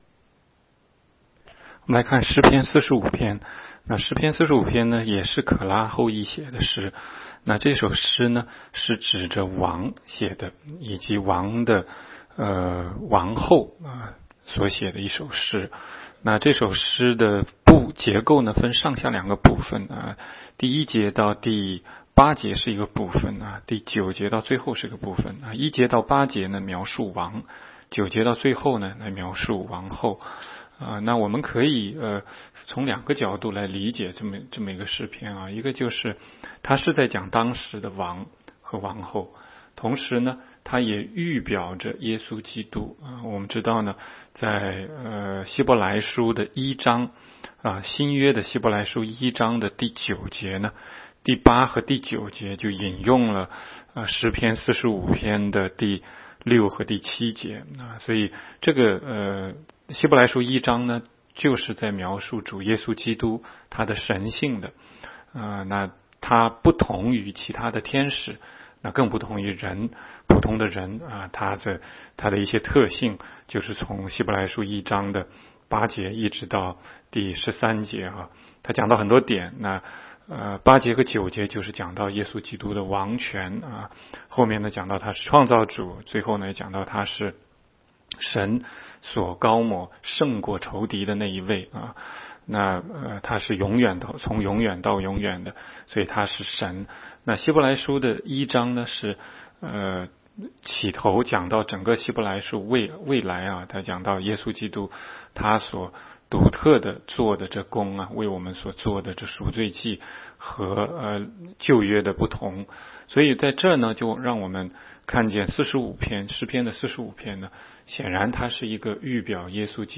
16街讲道录音 - 每日读经-《诗篇》45章